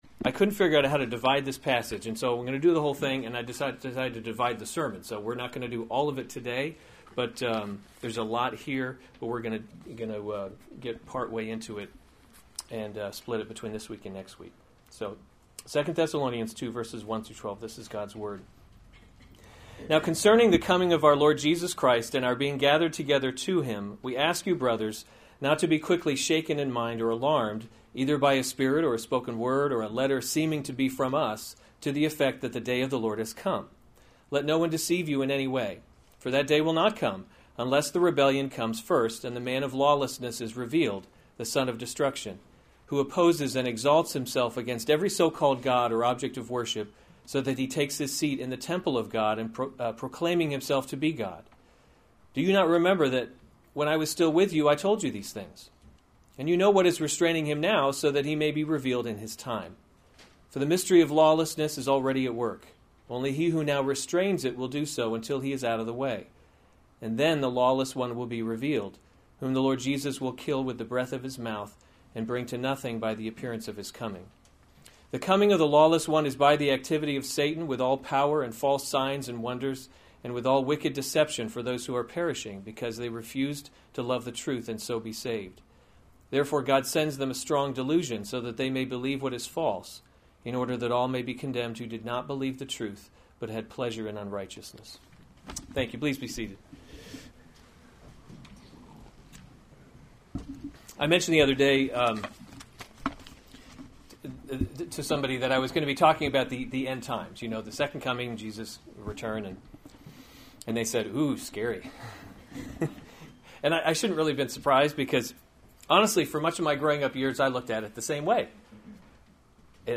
May 21, 2016 2 Thessalonians – The Christian Hope series Weekly Sunday Service Save/Download this sermon 2 Thessalonians 2:1-12 Other sermons from 2 Thessalonians The Man of Lawlessness 2:1 Now concerning […]